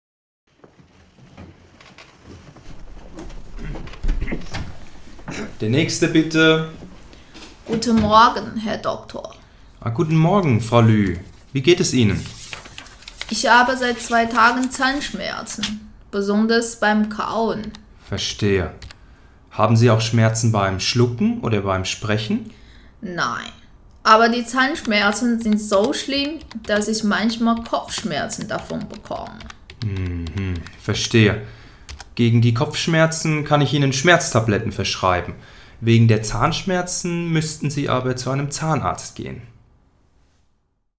Aktivität 2   Hören von Gesprächen zwischen Arzt und Patienten
Gespräch 2